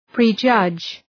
Προφορά
{prı’dʒʌdʒ}
prejudge.mp3